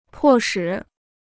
迫使 (pò shǐ) Dictionnaire chinois gratuit